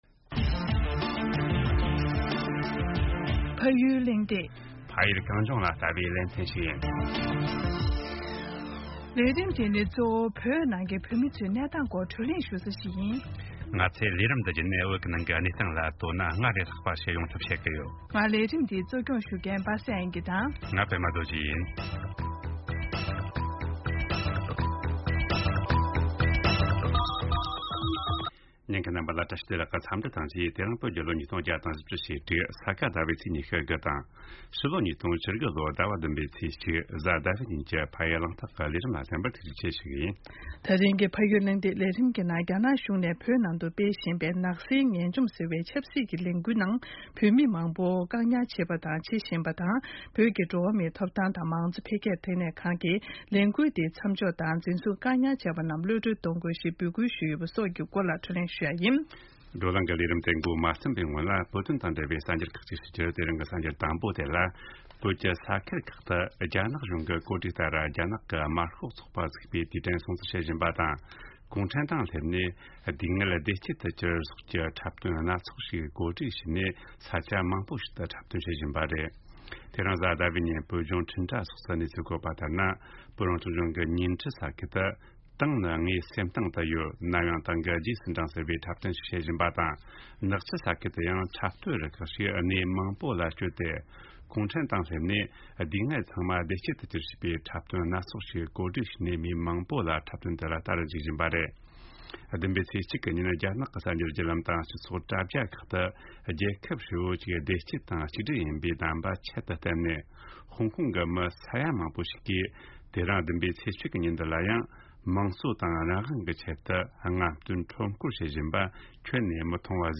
བགྲོ་གླེང་ཞུས་པ་ཞིག་གསན་རོགས་གནང་།